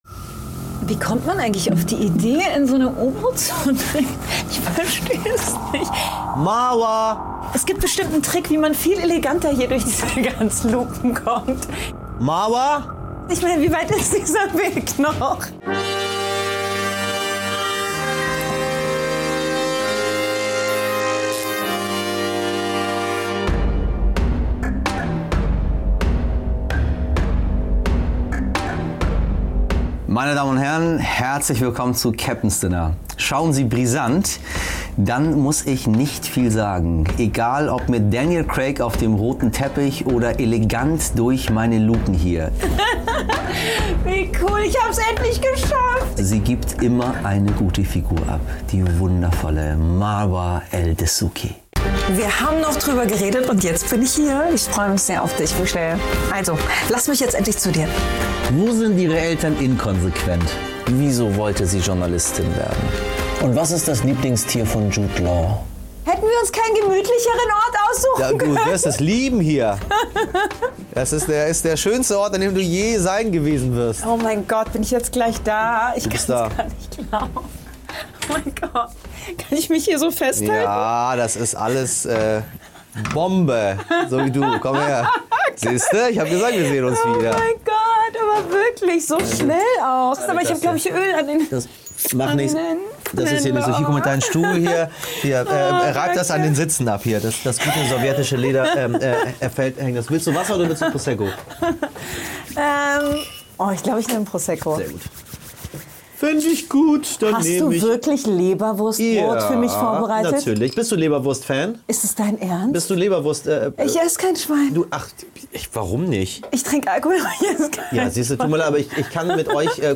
Bei Michel Abdollahi im U-Boot ist Brisant-Moderatorin Marwa Eldessouky zu Gast.